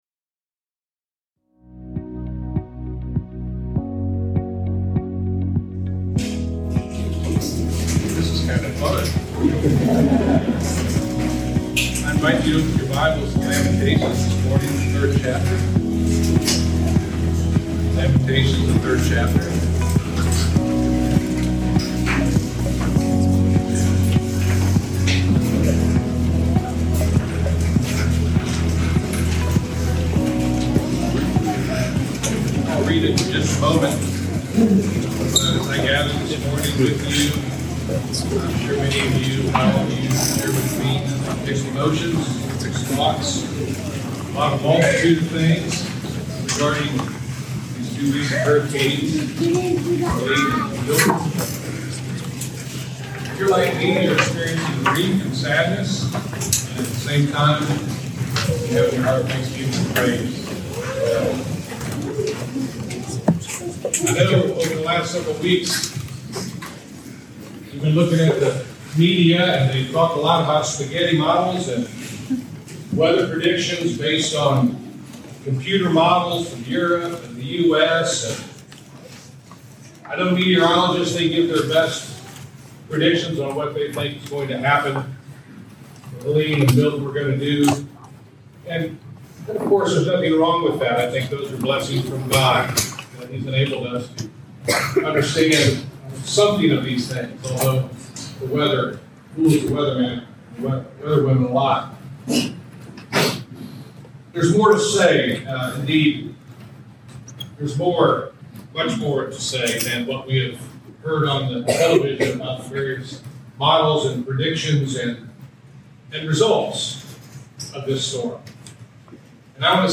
As our church family gathers together, at Westchase Elementary after Hurricane Milton, let us remember the promises God gives us that He is in control and He will deliver us from the storms in our lives. We look to Lamentations 3 and are reminded that the steadfast love of the Lord never ceases – His mercies never come to an end.